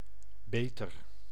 English: Dutch pronunciation of "beter"